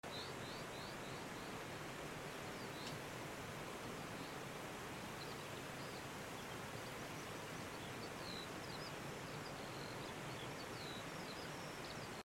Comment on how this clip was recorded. Walking along the crystal-clear waters of this beautiful river, I found myself surrounded by countless monarch butterflies dancing in the sunlight as they migrate through Oregon.